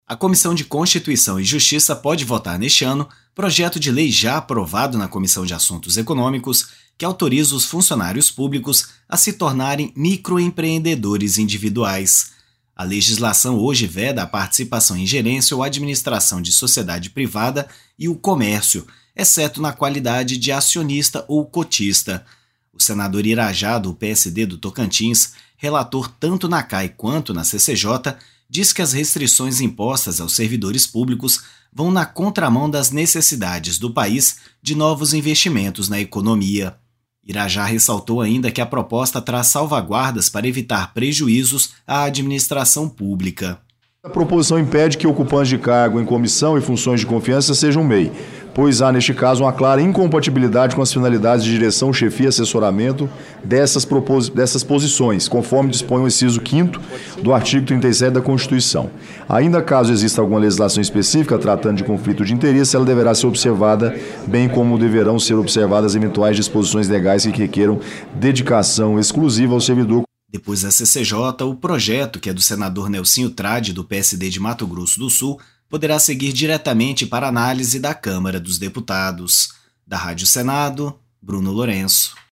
Proposta, que está pronta para ser votada na Comissão de Constituição e Justiça, proíbe, no entanto, quem ocupa cargo em comissão ou exerça função de confiança de atuar como MEI, como explicou o relator, senador Irajá (PSD-TO).